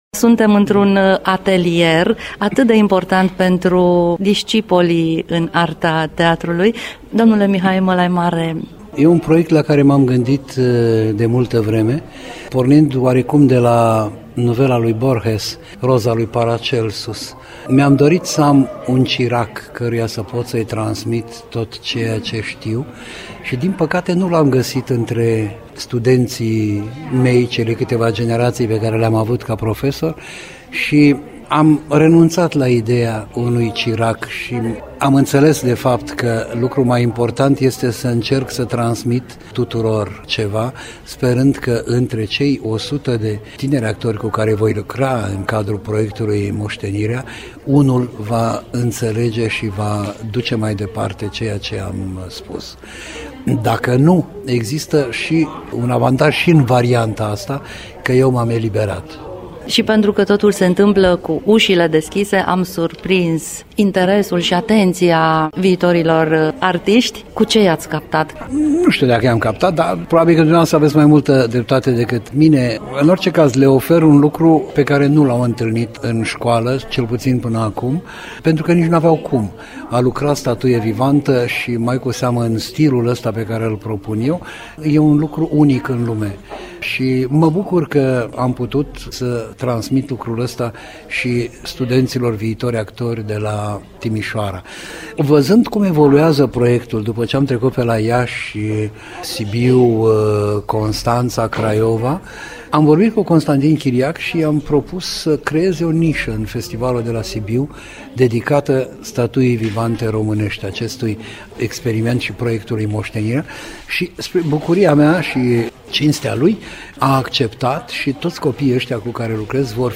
Actorul Mihai Mălaimare, iniţiator al proiectului „Moştenirea”, interviu pentru Radio Timişoara:
Interviu-Mihai-Malaimare-actor.mp3